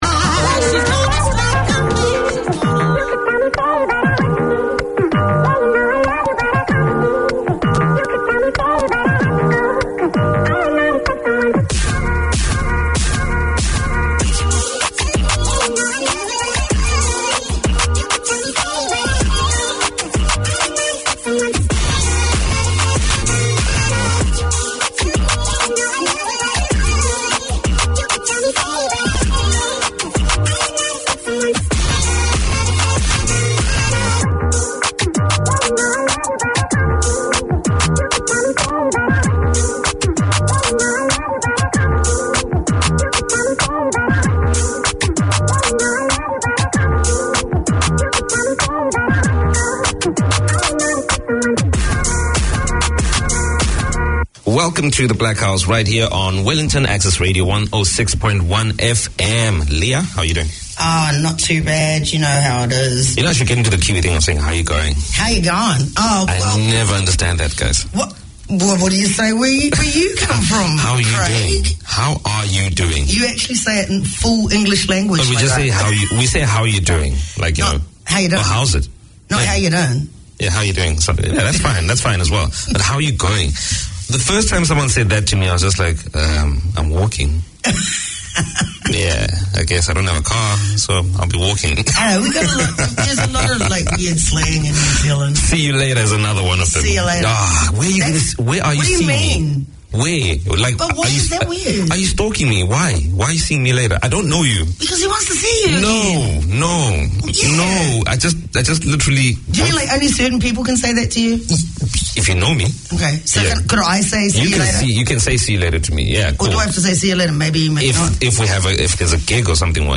Community Access Radio in your language - available for download five minutes after broadcast.
Pasifika Wire Live is a talanoa/chat show featuring people and topics of interest to Pasifika and the wider community.